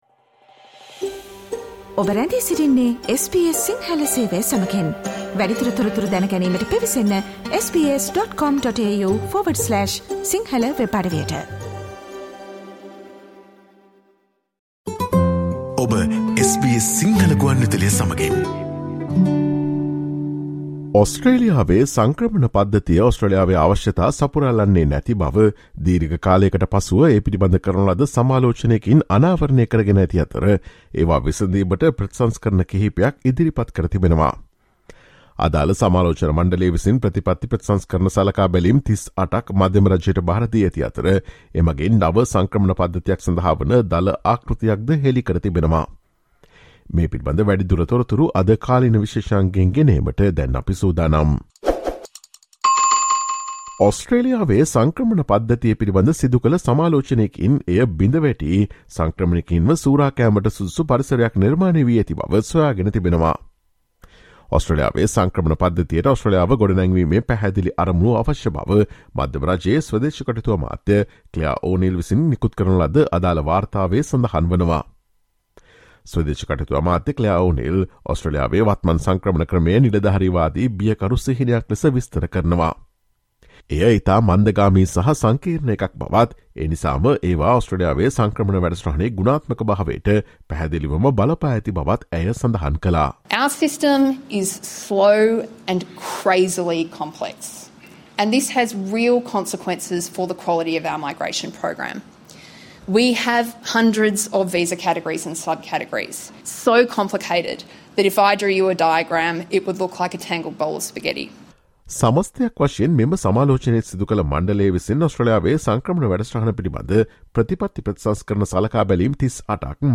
Listen to the SBS Sinhala radio current affair feature on Labor poised for the overhaul of the ‘broken’ migration system, changes for skilled workers and families expected.